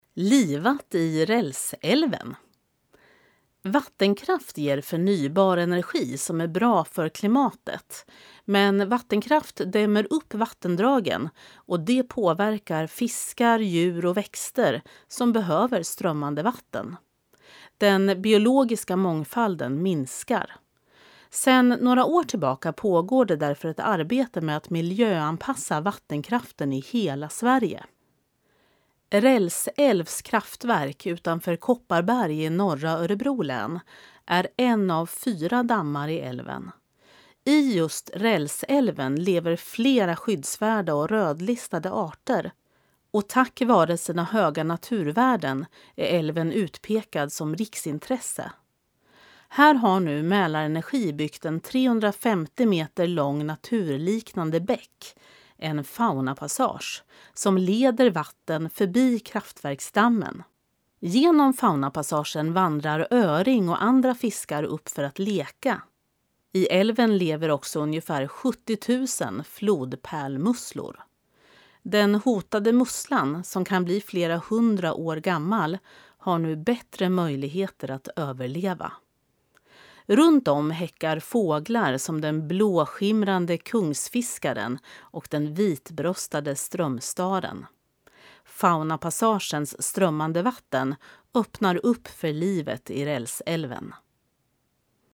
Klicka här för att lyssna till texten, inläst av en professionell uppläsare